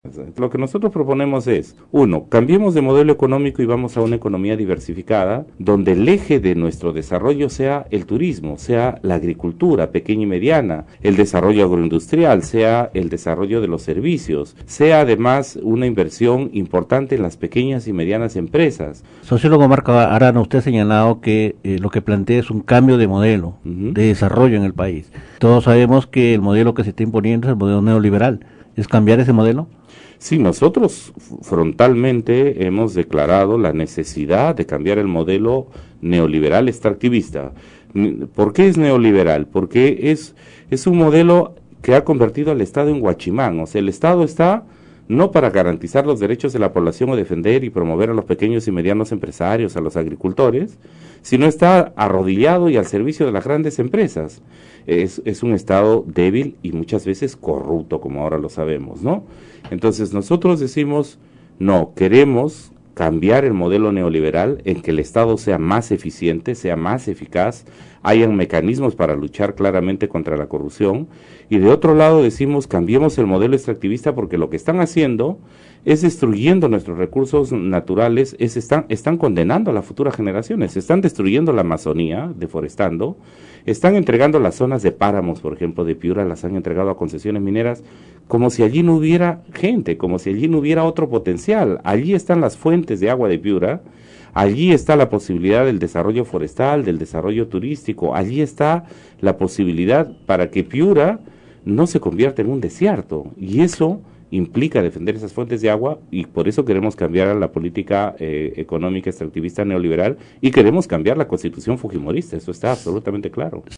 En declaraciones en Radio Cutivalú, dijo que el modelo neoliberal ha convertido al Estado en ineficiente, débil, corrupto y al servicio de las grandes empresas privadas.